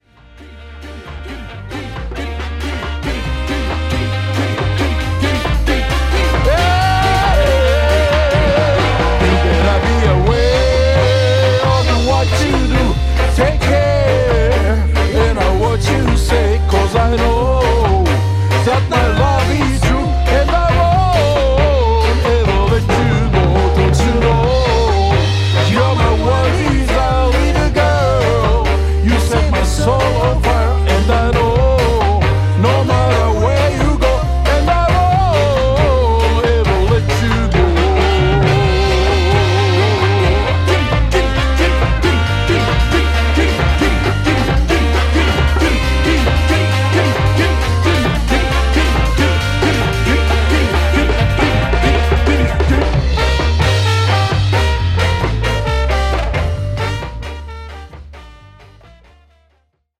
ホーム ｜ JAMAICAN MUSIC > 日本のレゲエ